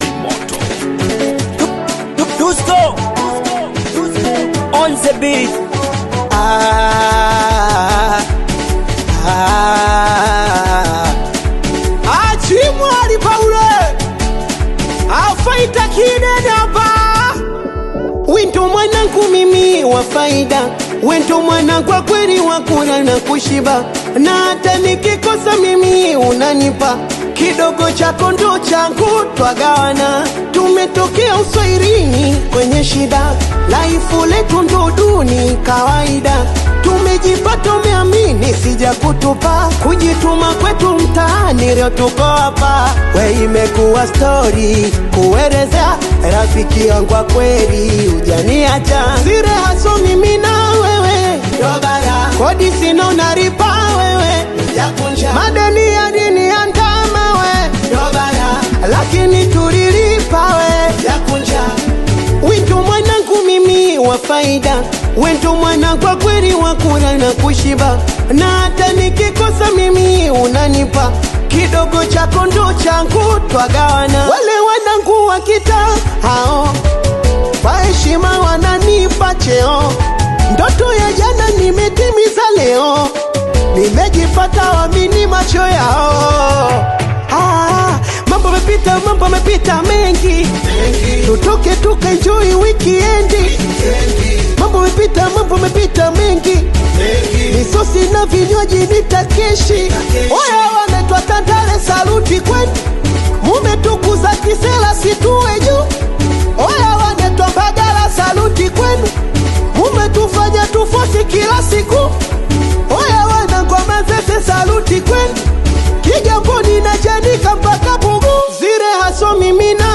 Singeli music track
Singeli